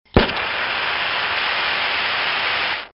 Звук воспламенения сигнальной комбинированной ракеты